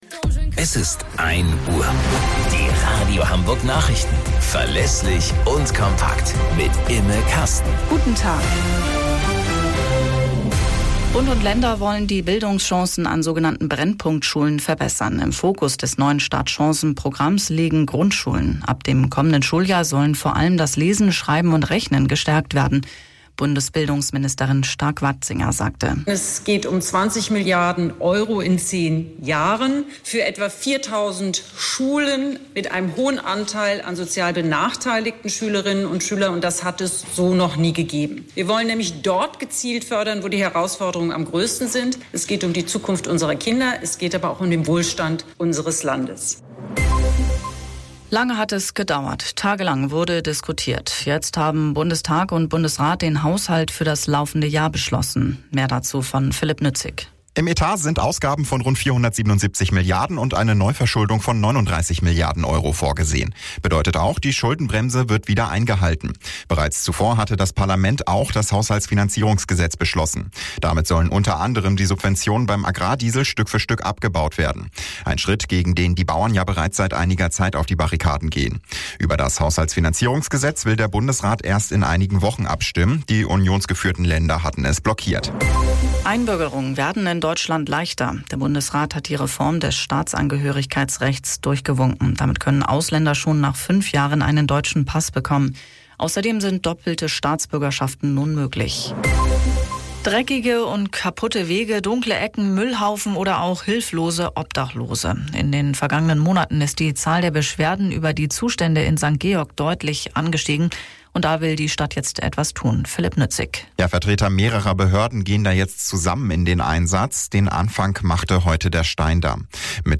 Radio Hamburg Nachrichten vom 06.04.2024 um 11 Uhr - 06.04.2024